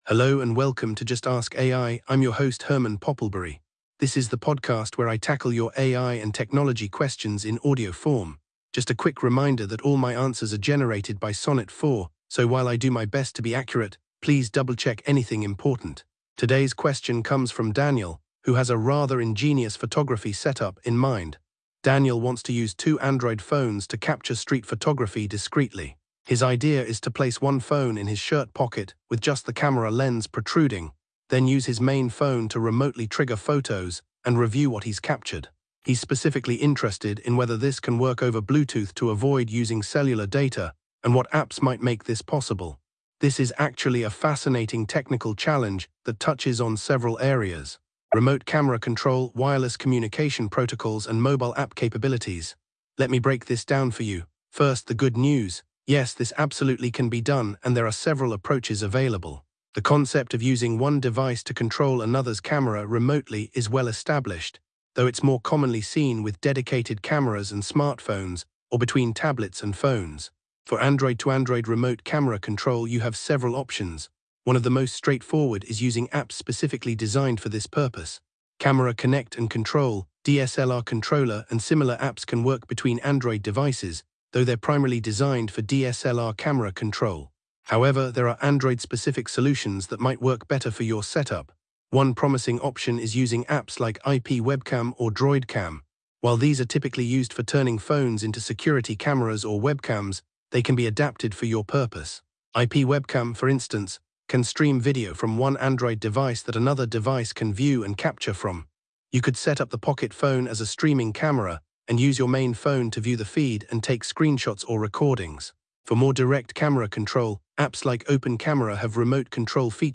AI-Generated Content: This podcast is created using AI personas.
This episode was generated with AI assistance. Hosts Herman and Corn are AI personalities.